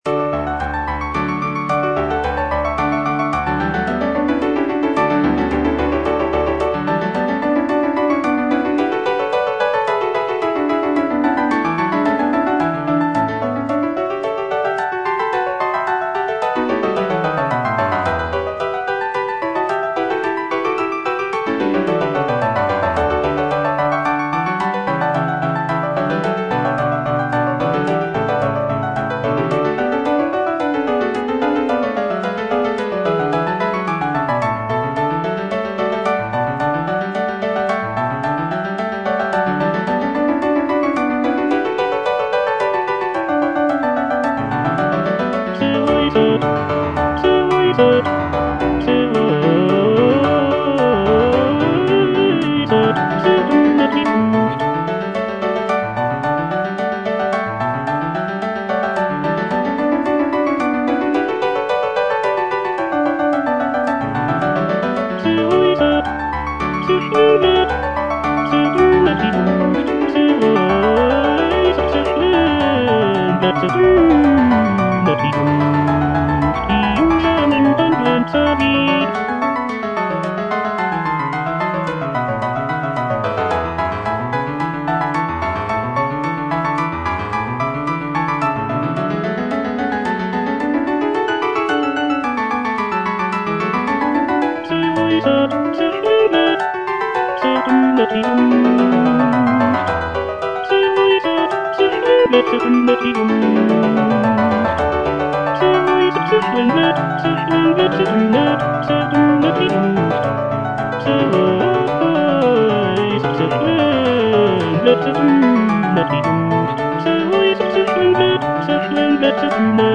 CANTATA
Tenor (Voice with metronome) Ads stop